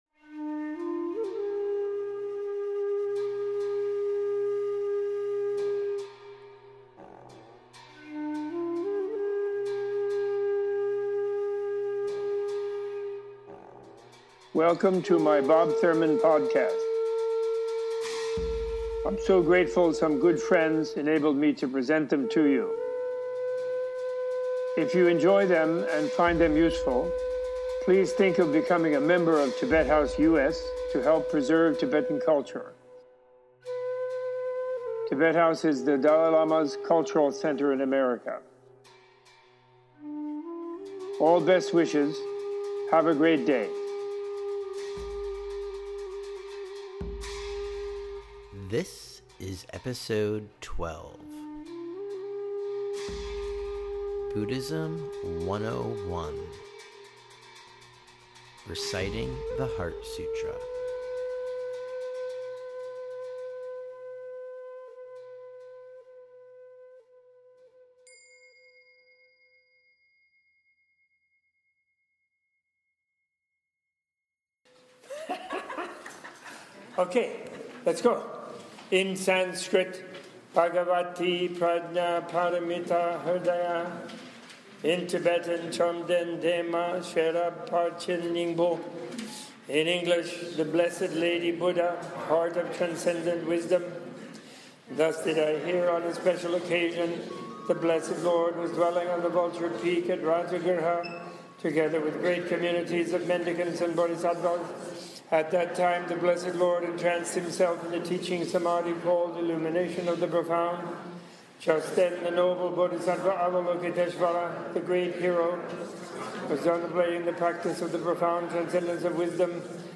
In this podcast Robert AF Thurman leads a recitation of The Heart Sutra and gives an introduction to it's history. Podcast concludes with Professor Thurman explaining how the practice of reciting The Heart Sutra is the key to understanding it's teaching as a tool for mind transformation.